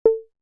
UI_SFX_Pack_61_53.wav